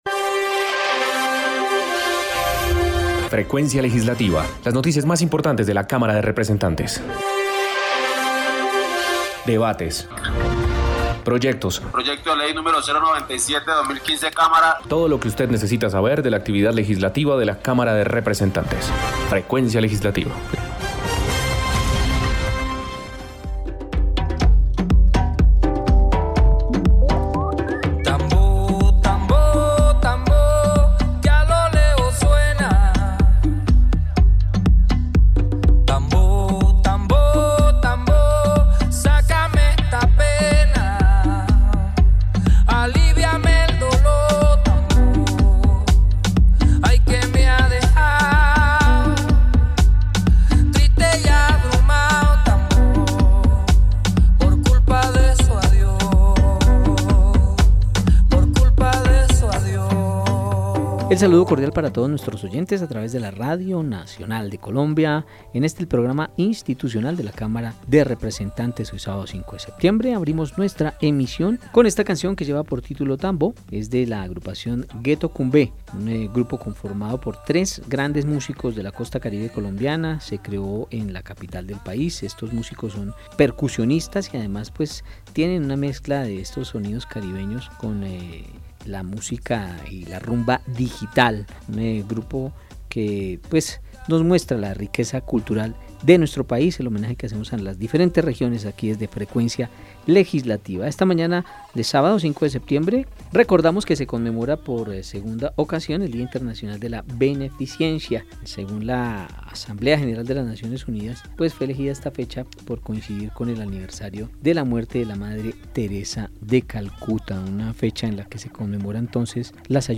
Programa Radial Frecuencia Legislativa. Sábado 5 de Septiembre de 2020